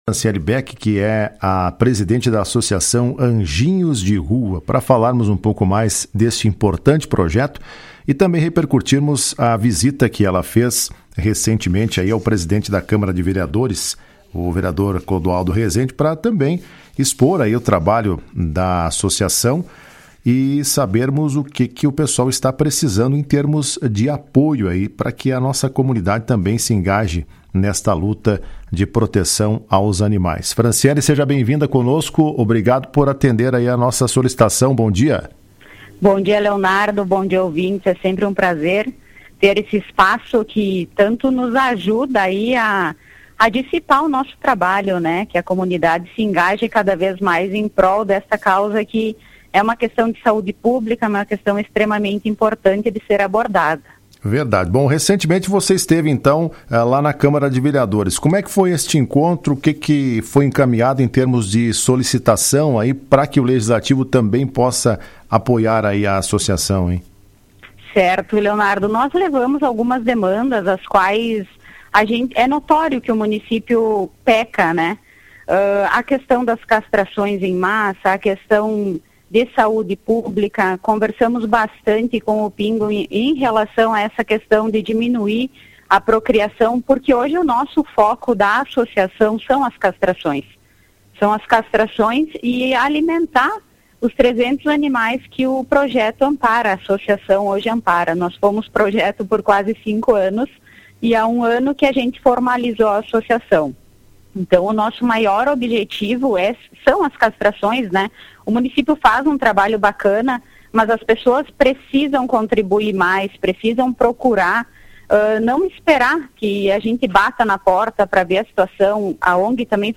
Em entrevista a Radio Esmeralda nesta terça-feira, ela revelou que o trabalho foca em campanhas de castração, para evitar a superpopulação tanto de cães quanto de gatos na cidade.